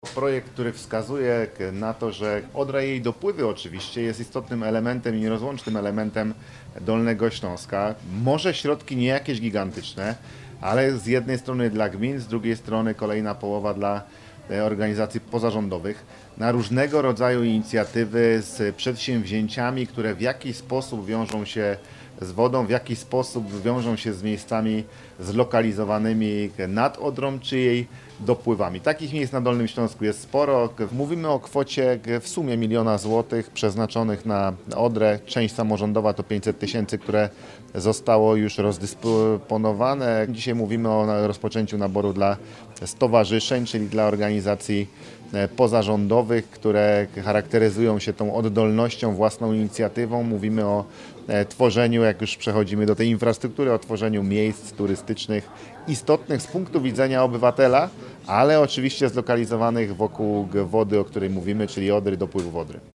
Program, którego celem jest ożywienie turystyczne rzek Dolnego Śląska, obejmuje zarówno wsparcie dla gmin, jak i organizacji pozarządowych. Mówi Paweł Gancarz, Marszałek Województwa Dolnośląskiego.